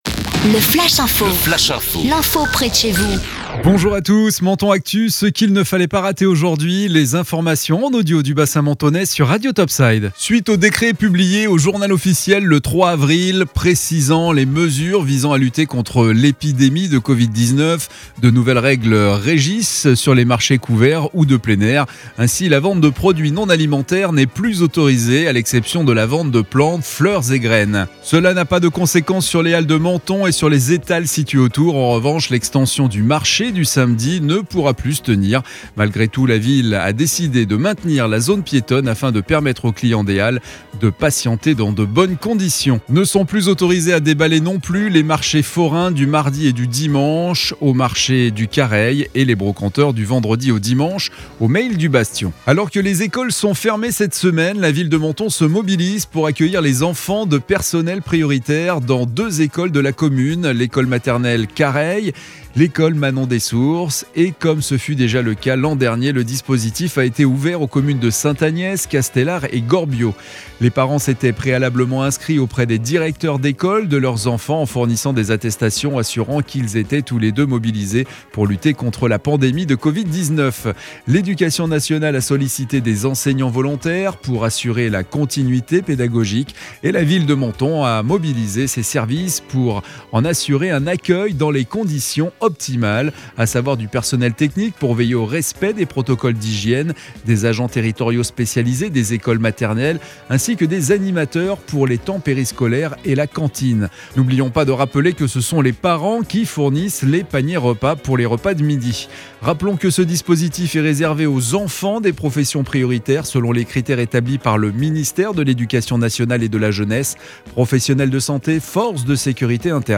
Menton Actu - Le flash info du mercredi 7 avril 2021